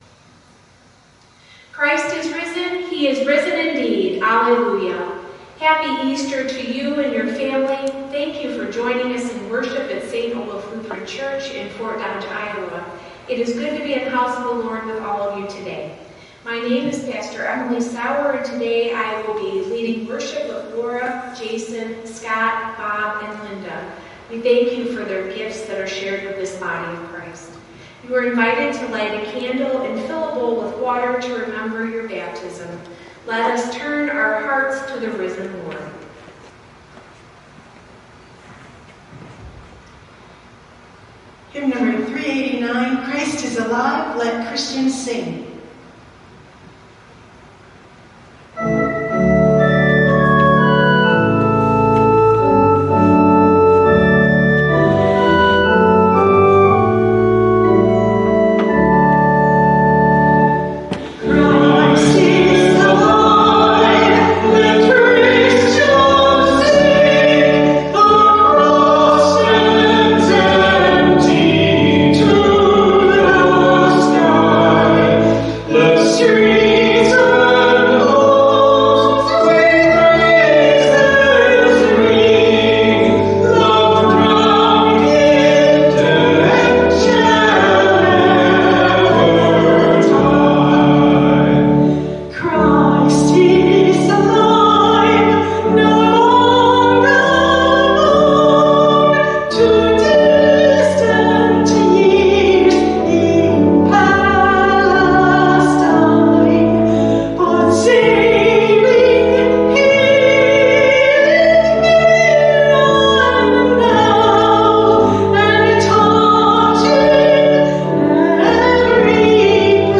Passage: Isaiah 1 Service Type: Sunday Worship Service